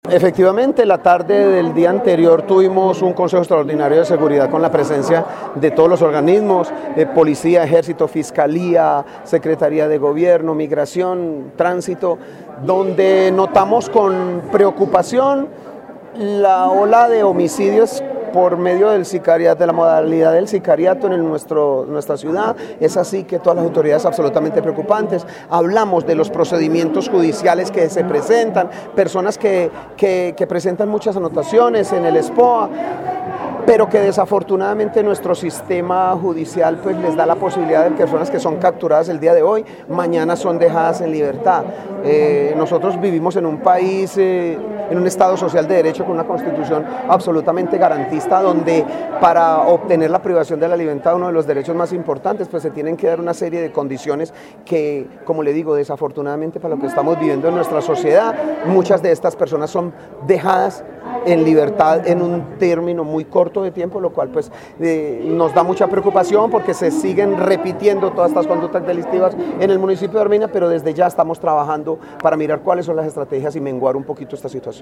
Alcalde de Armenia